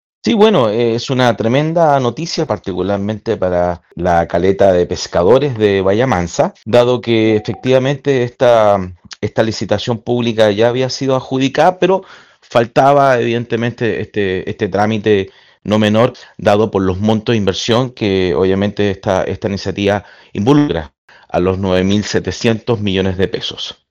Desde el municipio, el alcalde de San Juan de la Costa, José Luis Muñoz, describió el anuncio como una noticia esperada por años por la comunidad y el mundo pesquero local.